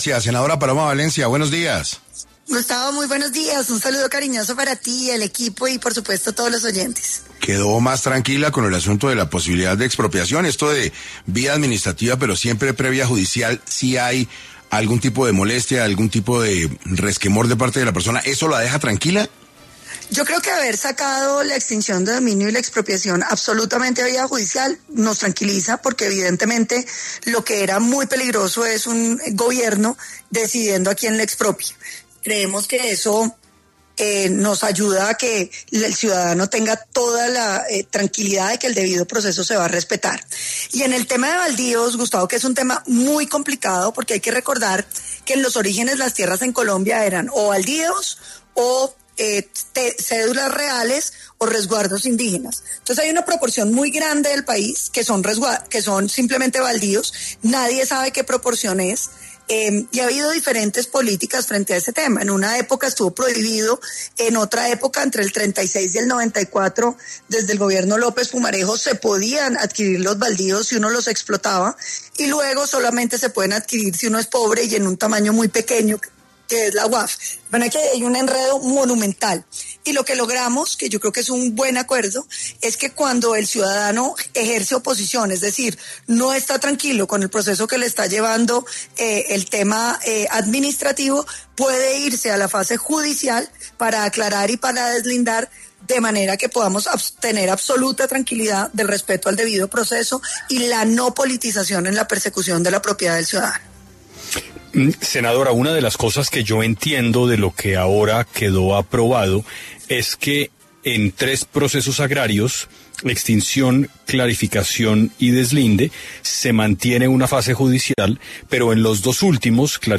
En 6AM de Caracol Radio estuvo la senadora Paloma Valencia, para hablar sobre lo que vendría para los colombianos con la Ley de Jurisdicción Agraria.